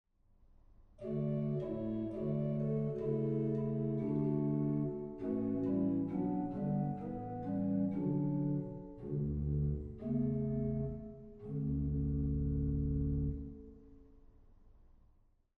The church room has a medium-wet acoustics with about 3,5 seconds of reverberation.
All stops were recorded with multiple release levels for short, medium and long key attacks.
Organ temperament: Slightly modified equal temperament at a1=440 Hz.
Bourdon 16
I-Bourdon16.mp3